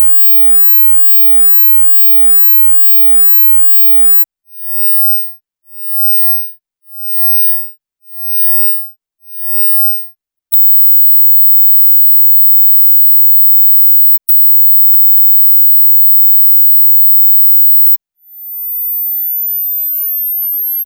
вот что выдаёт эта программа "Ультразвук" (аккуратней, не сожгите твиттер!!) да, формально это не ультразвук - 18 кГц, дети услышат точно.